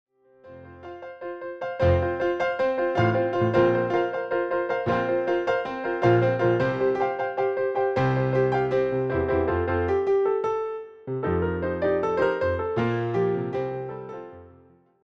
solo piano takes on Broadway material